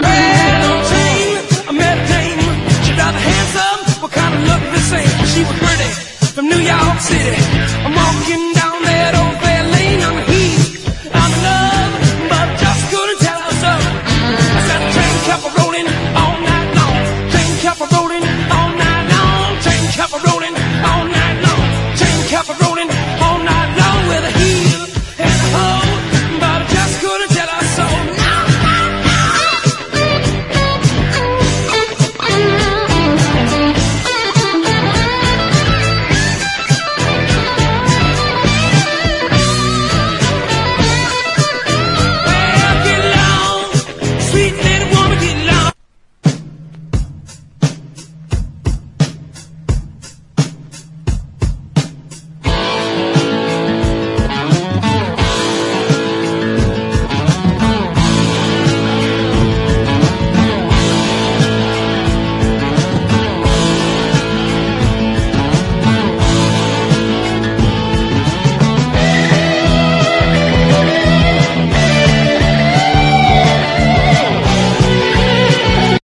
HARD ROCK / HEAVY METAL / DOOM
超低速重低音